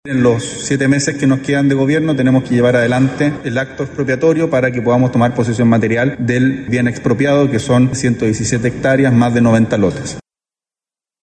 Al respecto, el ministro de Justicia y Derechos Humanos, Jaime Gajardo, explicó que el proceso se ejecutará dentro del mandato del actual gobierno.